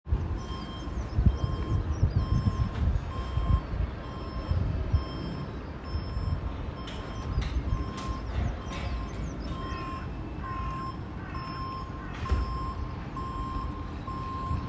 4. Reversing lory and crows
Park-Row.m4a